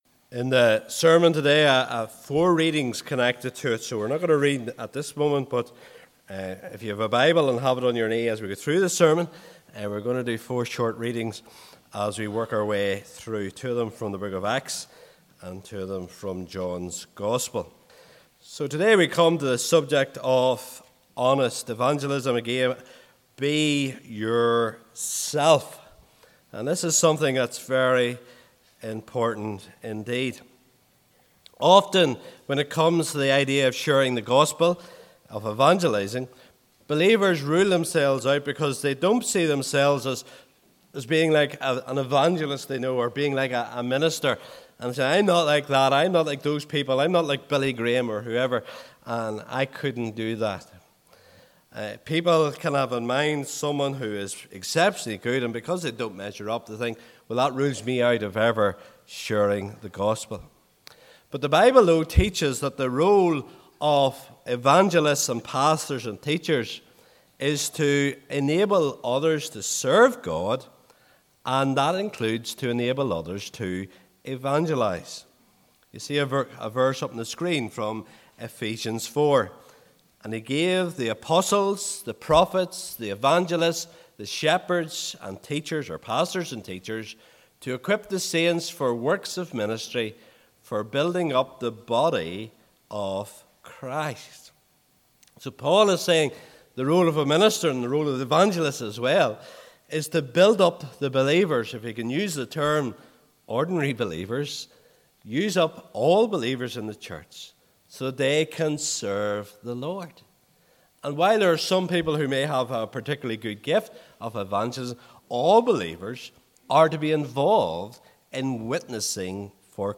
SERMON SERIES Honest Evangelism Part 1: Two Halves of the Story Part 2: Is it Worth it?